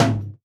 Tom_5.wav